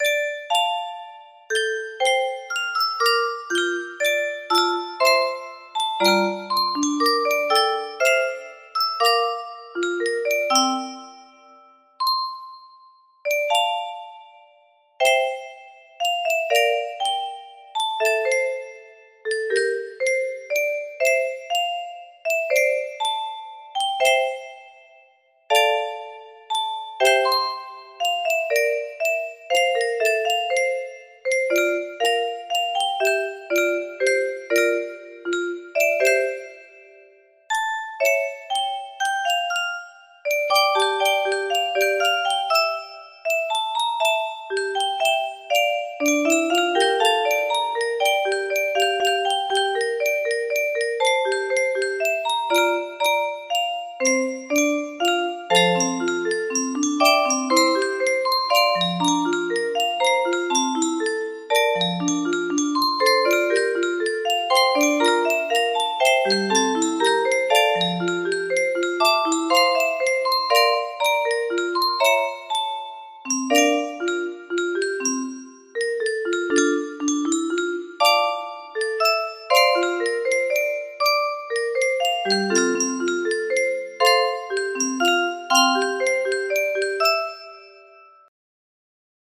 Sweet dreams, my dear music box melody
Grand Illusions 30 (F scale)